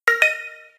mmsSent.ogg